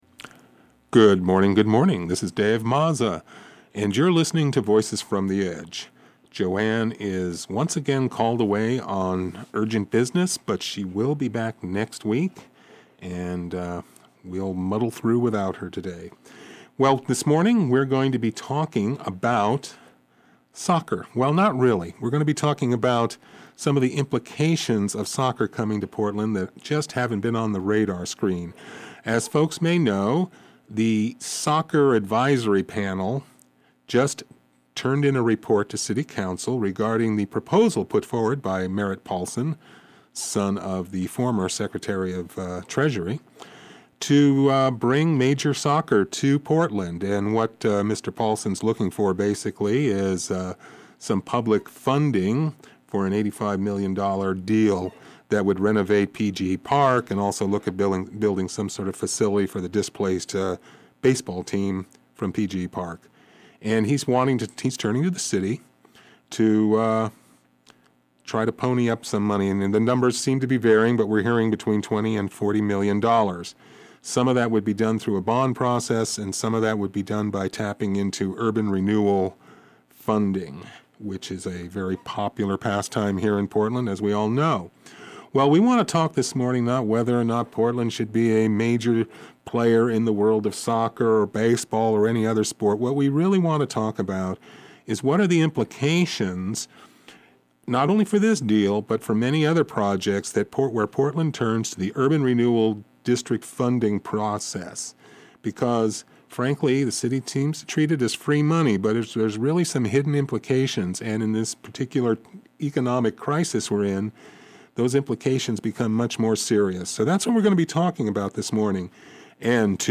Have a question for our guests, but can't call in during the program?